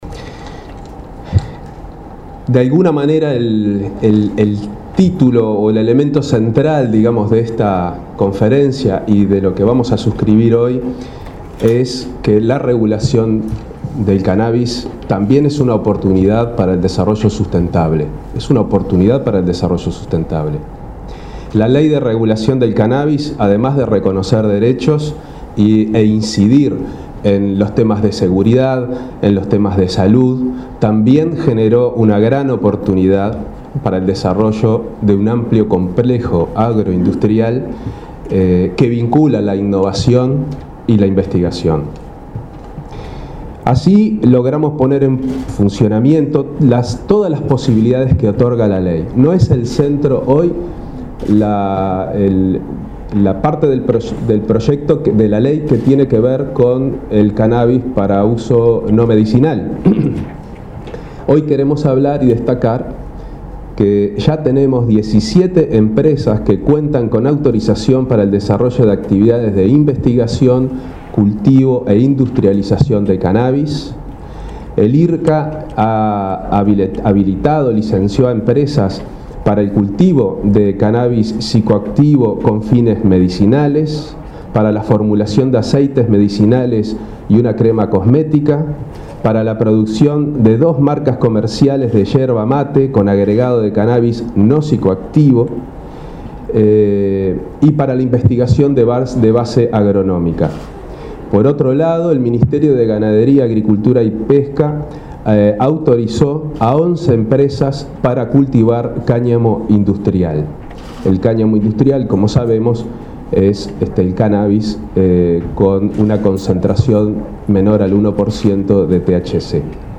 Un total de 17 empresas tienen autorización para actividades de investigación, cultivo o industrialización de cannabis, confirmó el prosecretario de Presidencia, Juan Andrés Roballo, en la firma de un acuerdo entre el IRCCA y Uruguay XXI para promover inversiones en el sector. Asimismo, Ganadería autorizó a 11 empresas a cultivar cáňamo industrial.